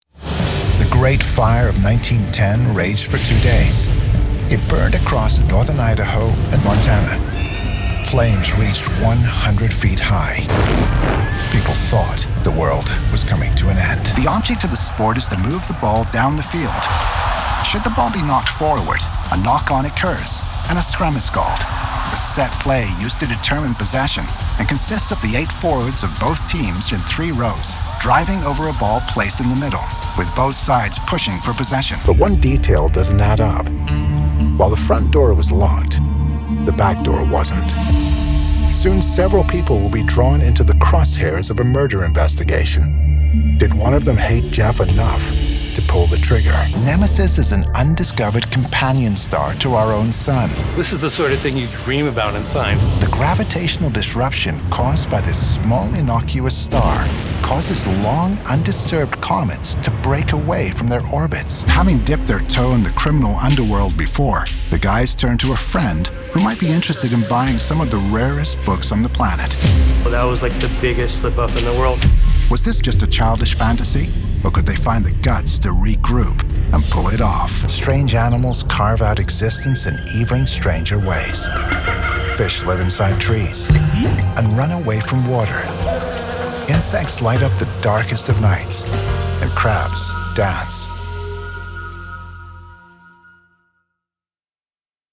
Englisch (Amerikanisch)
Natürlich, Unverwechselbar, Cool, Freundlich, Corporate
Unternehmensvideo